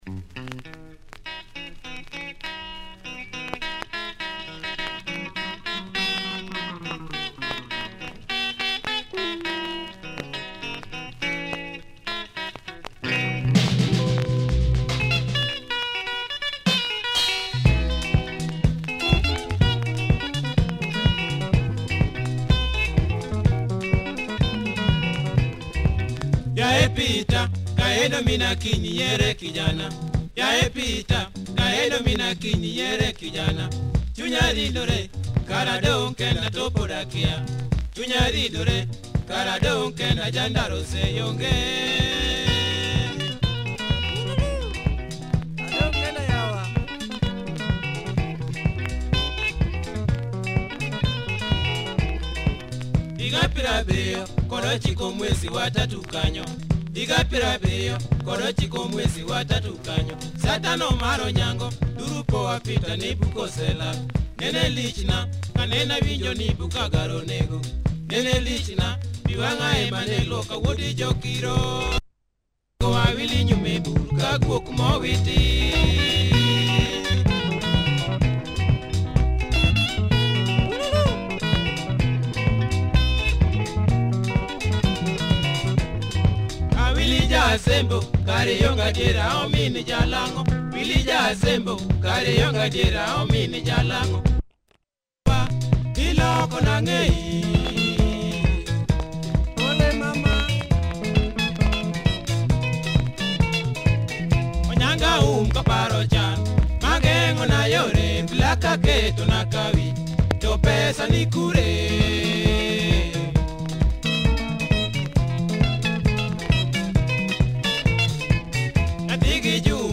Great luo benga with a good tempo.